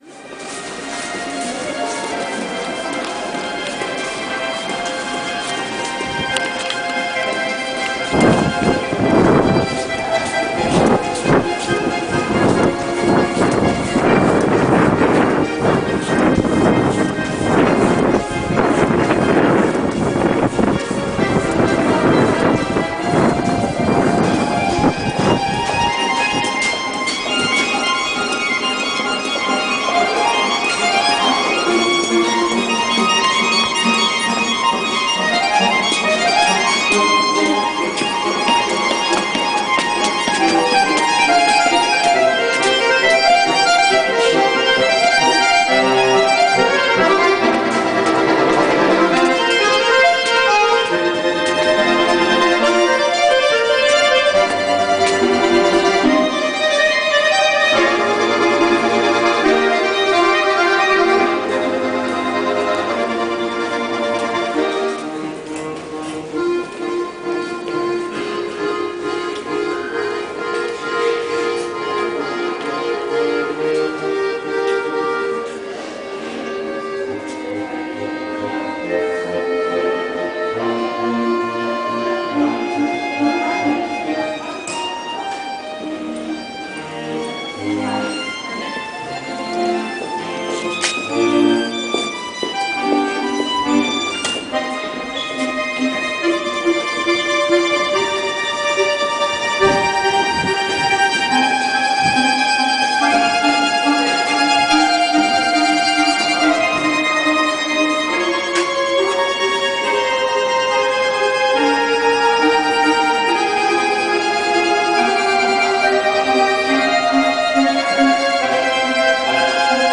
Im U-Bahntunnel Stadtmitte spielt ein Künstler (aus der Ukraine) auf seinem Akkordeon. Ich höre die fremden Klänge schon von weitem.